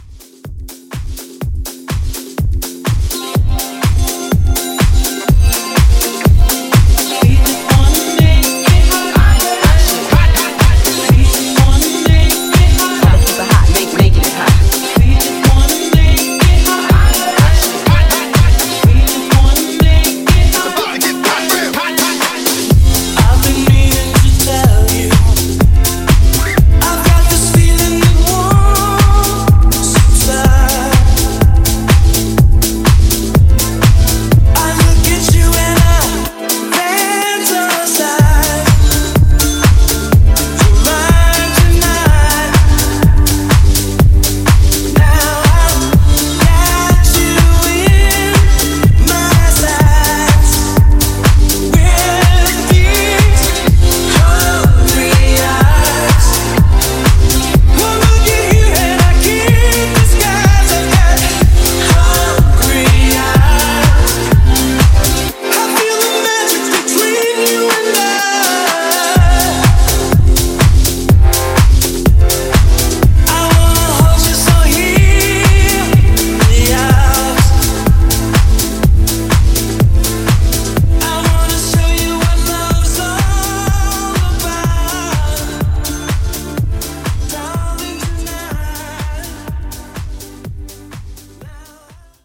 Genre: HIPHOP
Clean BPM: 95 Time